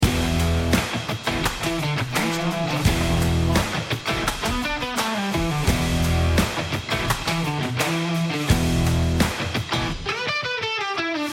Stomp Rock